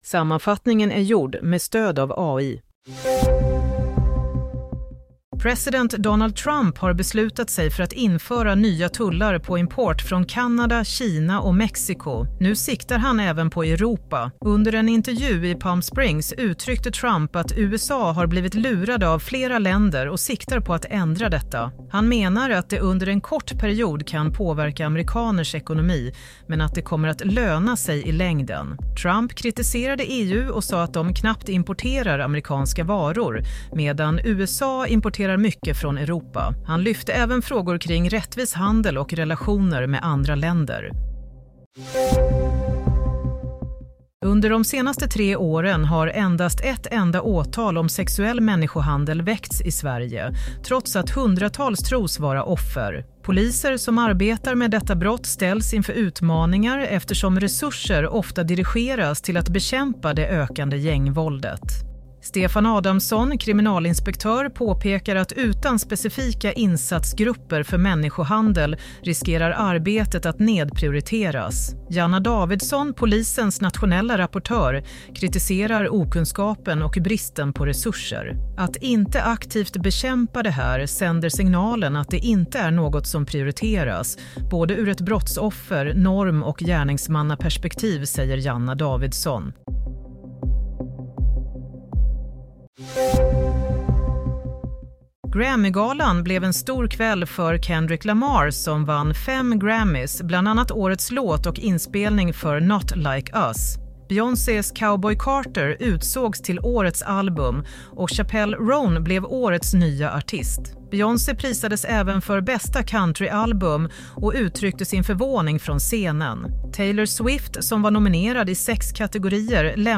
Play - Nyhetssammanfattning – 3 februari 08:40
Play Nyhetssammanfattning – 3 februari 08:40 Sammanfattningen av följande nyheter är gjord med stöd av AI. – Trump till attack mot Europa – Få dömda för människohandel – Vinnarna från The Grammys inatt Broadcast on: 03 Feb 2025 Summary Sammanfattningen av följande nyheter är gjord med stöd av AI.